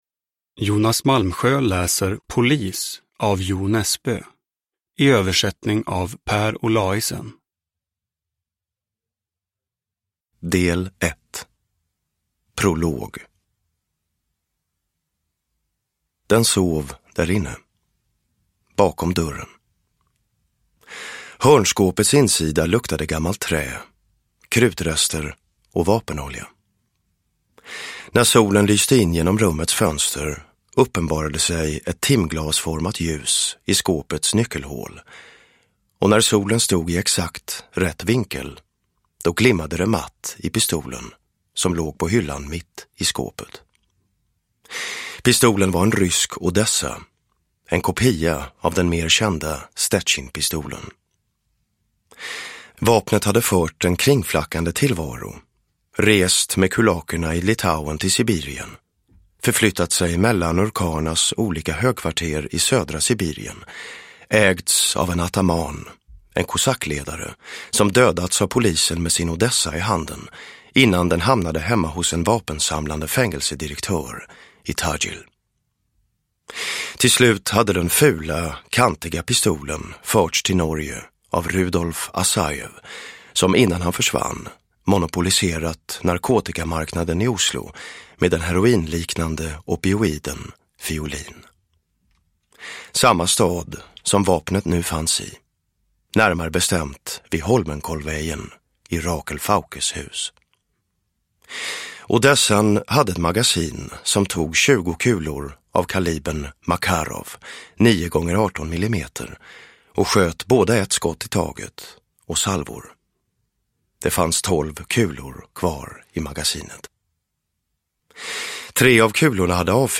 Polis – Ljudbok – Laddas ner
Uppläsare: Jonas Malmsjö